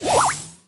item_swirl_01.ogg